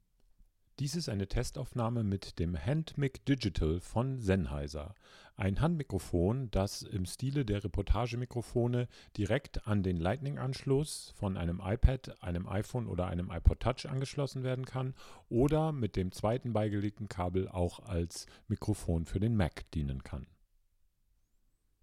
Wir haben das Handmic Digital am iPhone und am iPad pro ausprobiert.
Zwei Testaufnahmen haben wir auch gemacht.